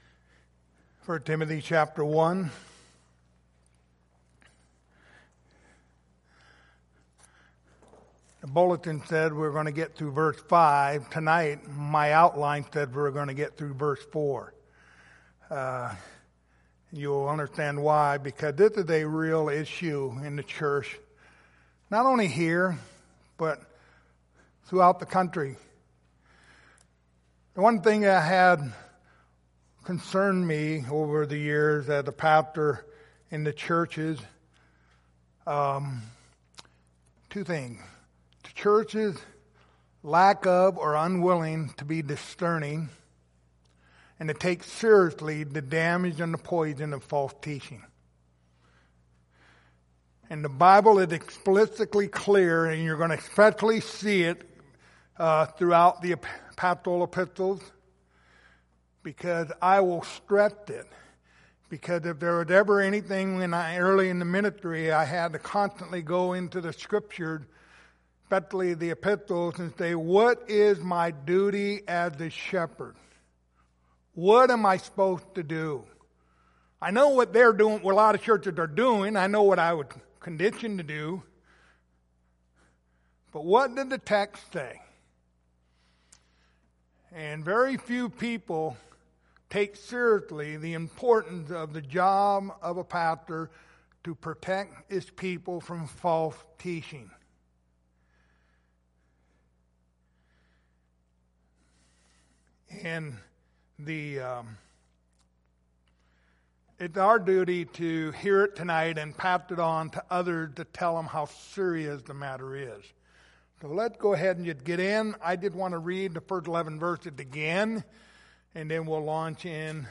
Passage: 1 Timothy 1:3-4 Service Type: Sunday Evening